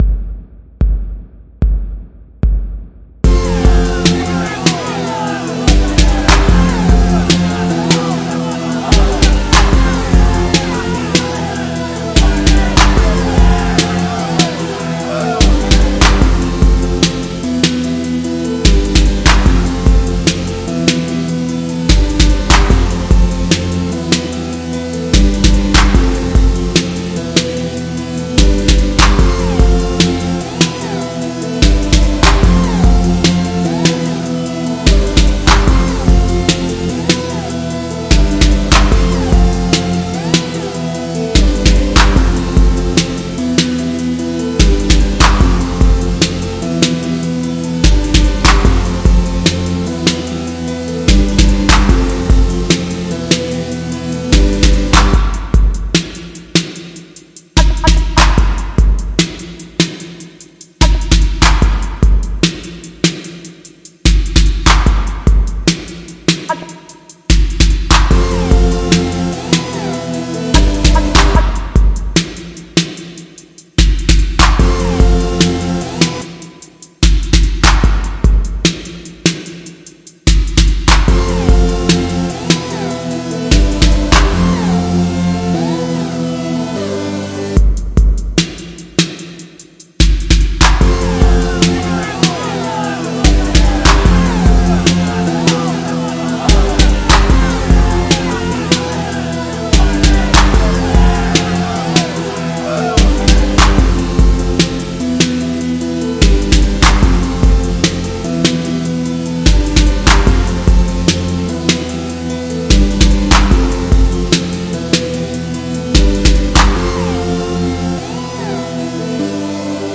Scrapped beat